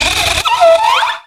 Cri de Cheniselle dans Pokémon X et Y.